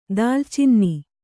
♪ dālcinni